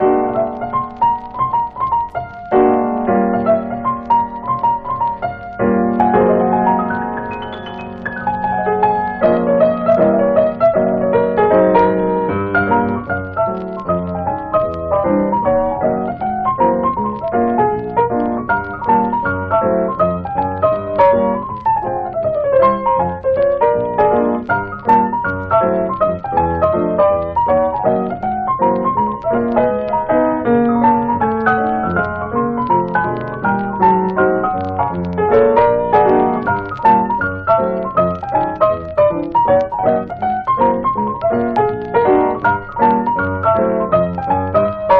Jazz, Blues, Ragtime　USA　12inchレコード　33rpm　Mono